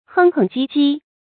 哼哼唧唧 hēng hēng jī jī 成语解释 形容说话装模作样，拿腔拿调。